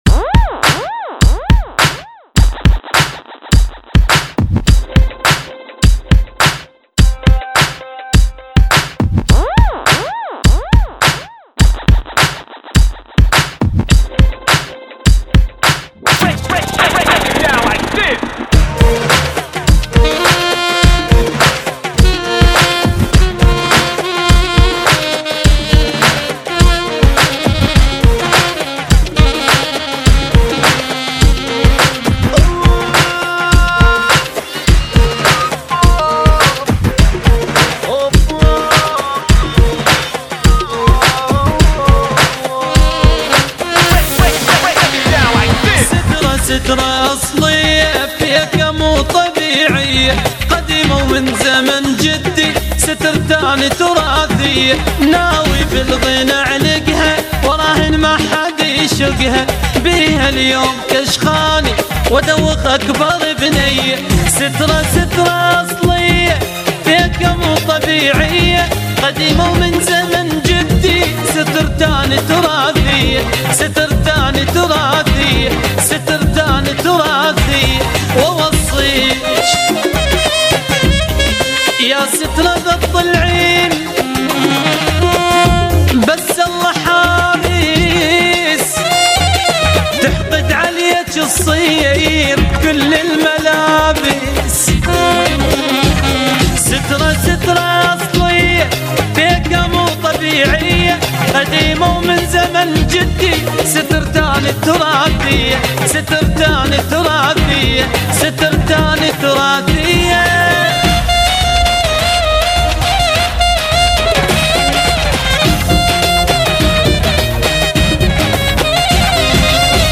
(104 BPM)